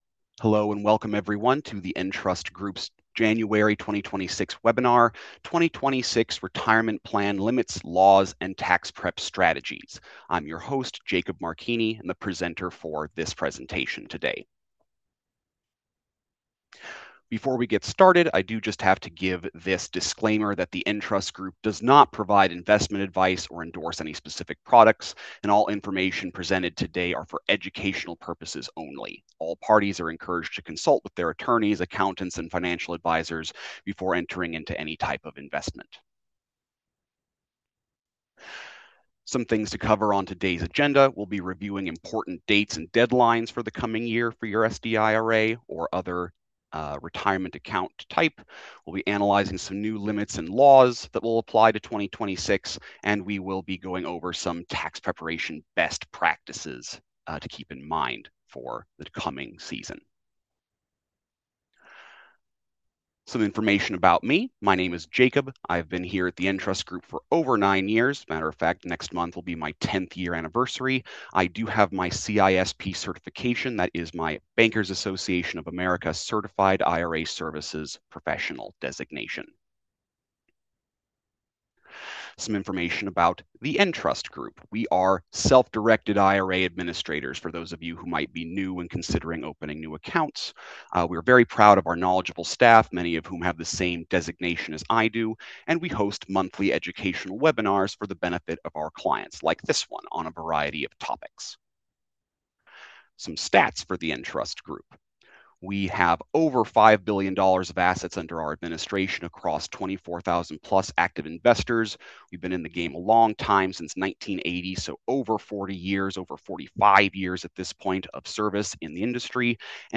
Webinar_January_2026_Audio_Replay.m4a